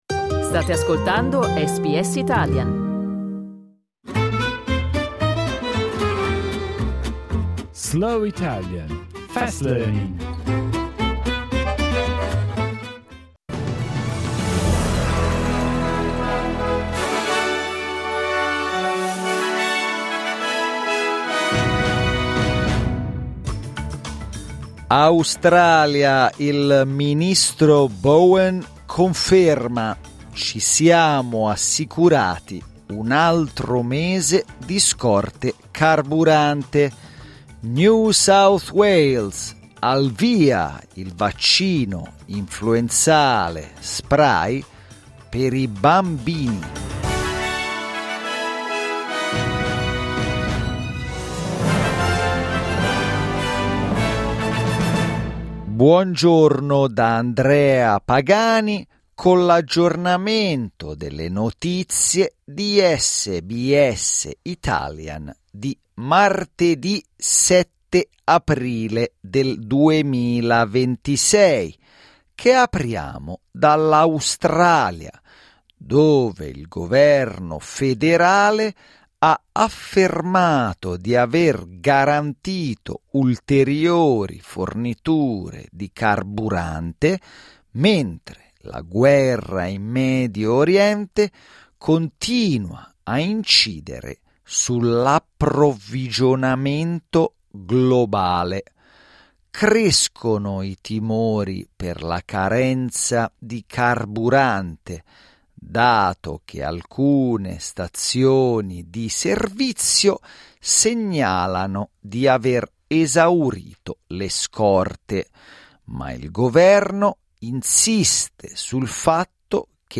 SBS Italian News bulletin, read slowly.